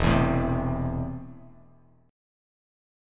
Esus4#5 chord